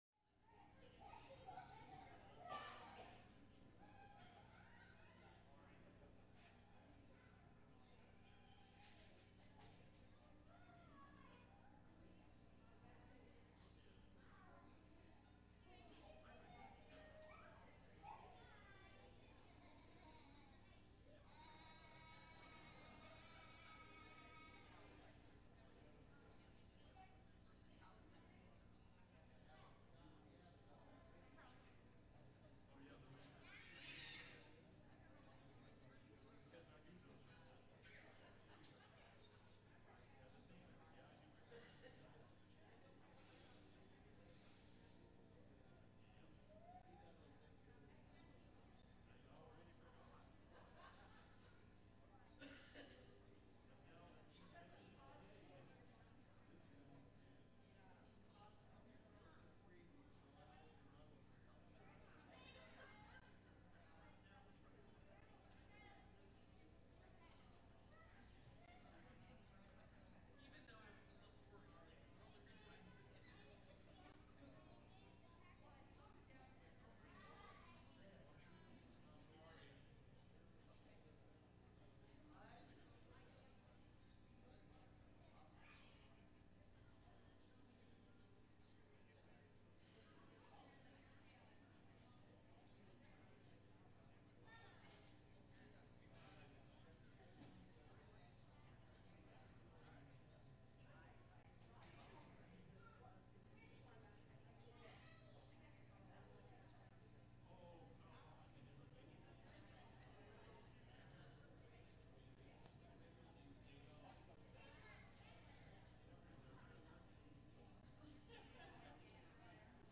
11_7-21-sermon.mp3